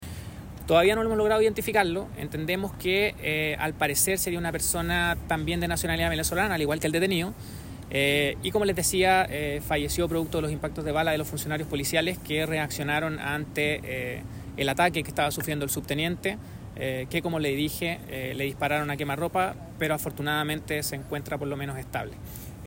El fiscal Felipe Olivari, de la Fiscalía Centro Norte, confirmó que el individuo fallecido aún no ha sido identificado, aunque se presume que es de nacionalidad venezolana, al igual que su acompañante detenido, un hombre de 24 años en situación migratoria irregular.